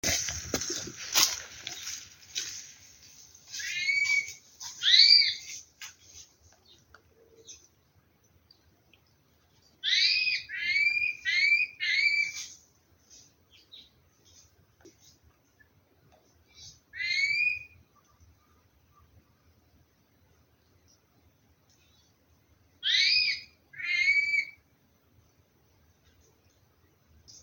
Saltarín de Yelmo (Chiroxiphia galeata)
Nombre en inglés: Helmeted Manakin
Localización detallada: Pouso alegre y alrededores
Condición: Silvestre
Certeza: Vocalización Grabada
helmeted-manaquin.mp3